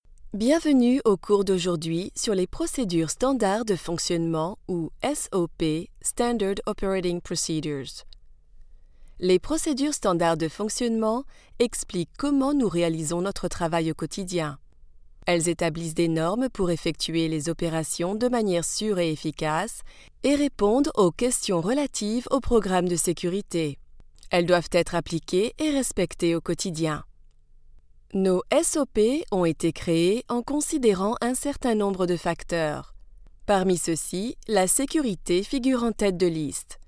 Native French Voice Over artist.
Professional home studio, based in New York. 15+ years experience.
Sprechprobe: eLearning (Muttersprache):